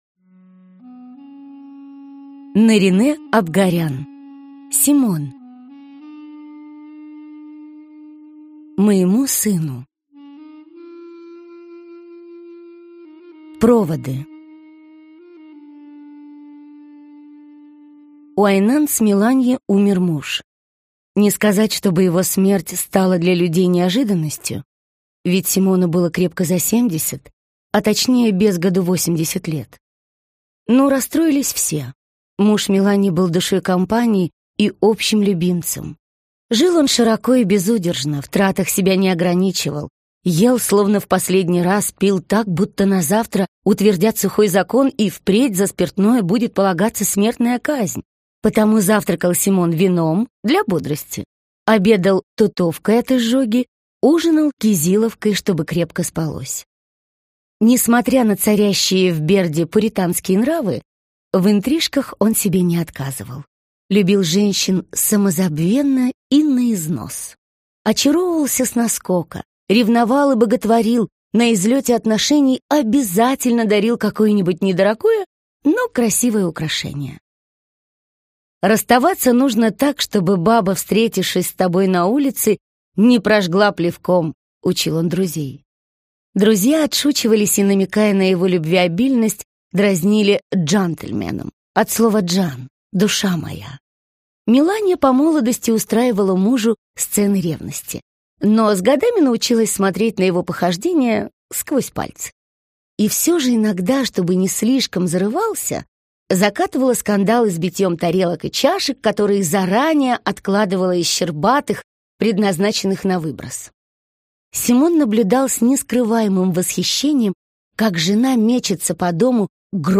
Аудиокнига Симон - купить, скачать и слушать онлайн | КнигоПоиск